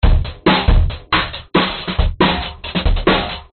Drum Loop 011 (138 BPM)
Tag: 节拍 休息 时髦 循环 老式 胖子